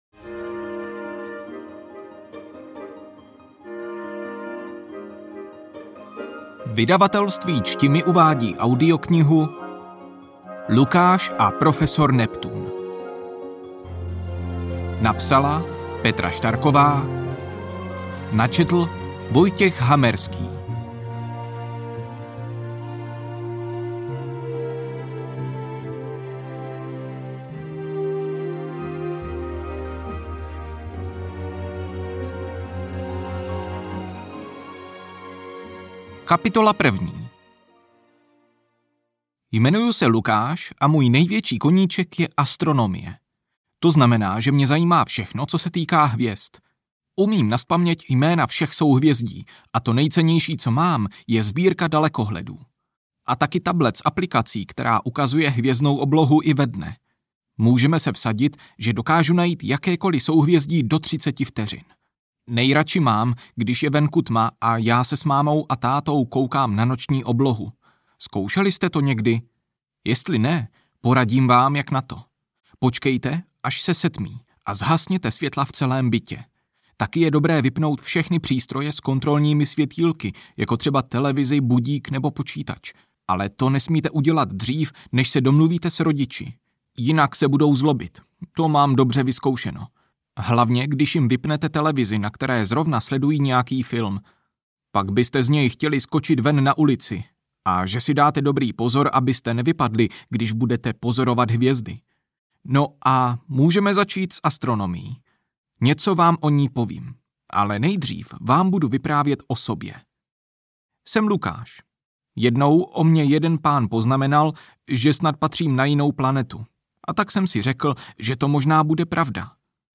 Lukáš a profesor Neptun audiokniha
Ukázka z knihy